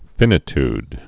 (fĭnĭ-td, -tyd, fīnĭ-)